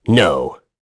Lusikiel-Vox-Deny1.wav